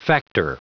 Prononciation du mot factor en anglais (fichier audio)
Prononciation du mot : factor